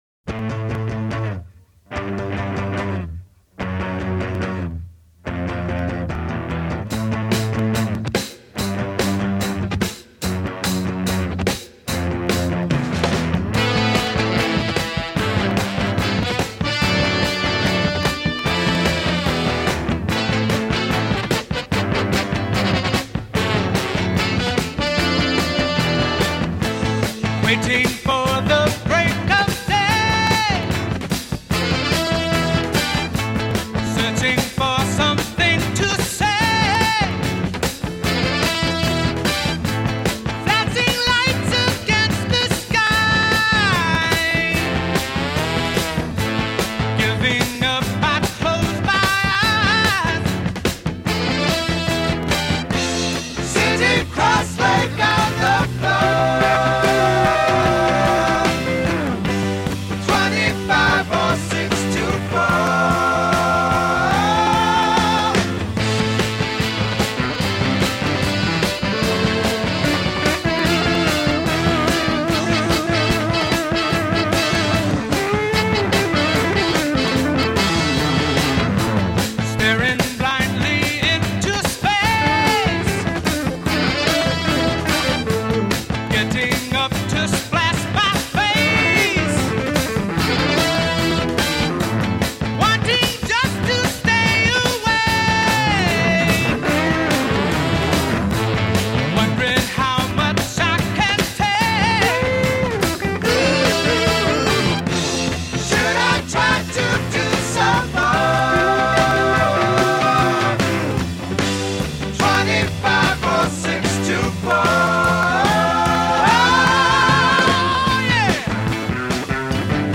is big and brassy
love that rousing finish.